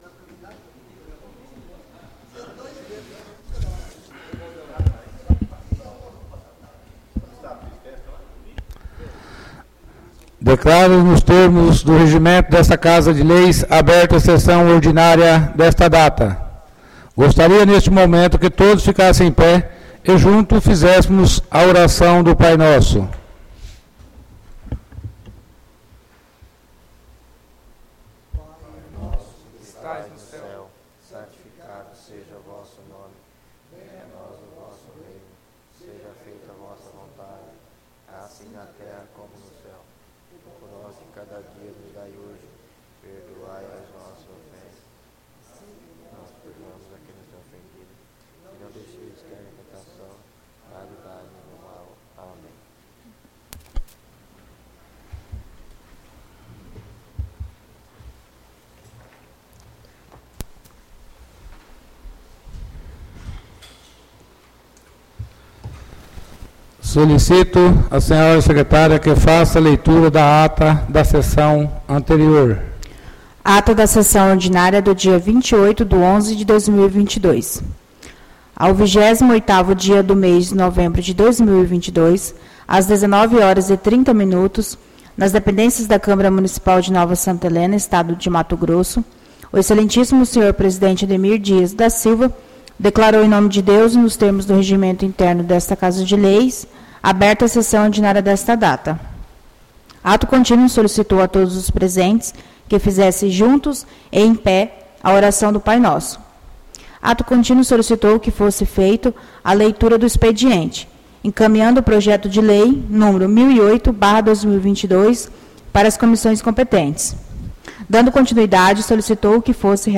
ÁUDIO SESSÃO 05-12-22 — CÂMARA MUNICIPAL DE NOVA SANTA HELENA - MT